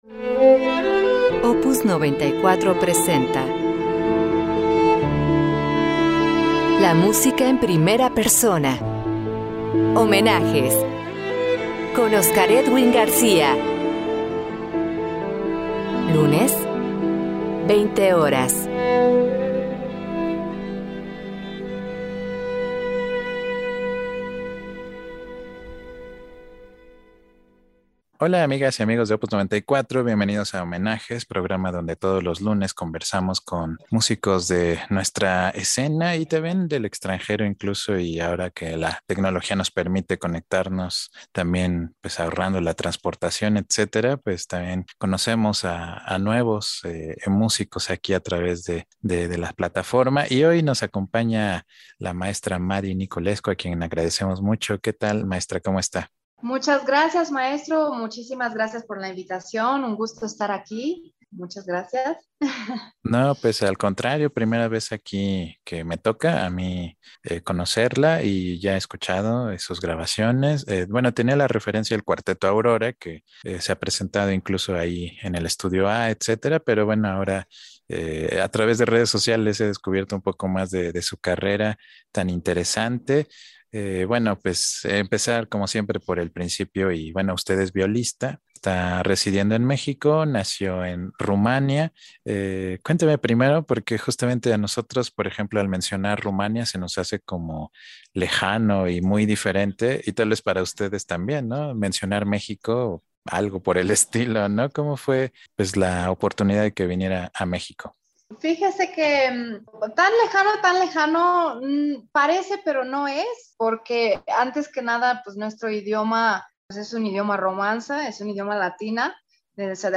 Conversación